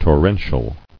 [tor·ren·tial]